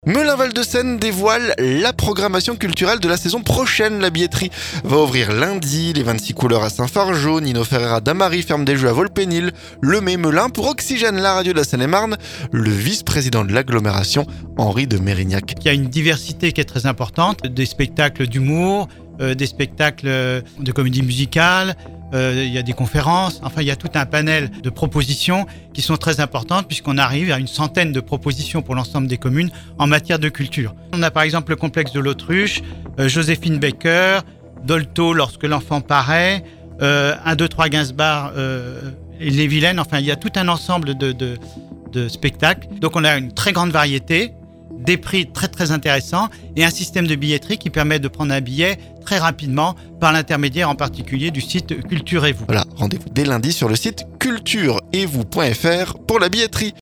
Pour Oxygène la radio de la Seine-et-marne le vice-président de l'agglomération Henri de Meyrignac.